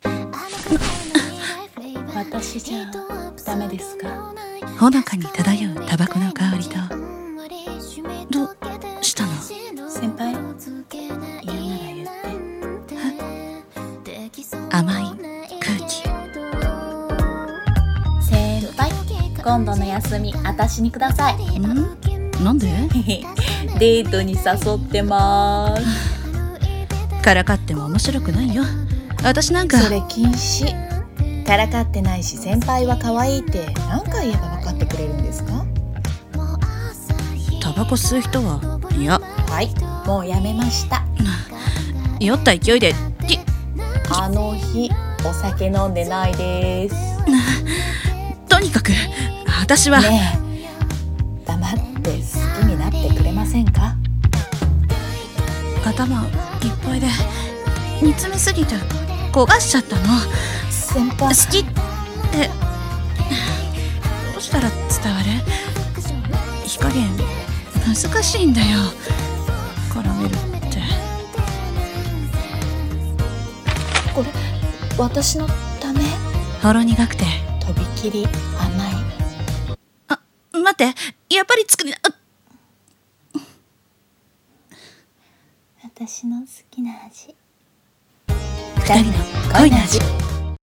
【声劇】こがれる、きゃらめりぜ。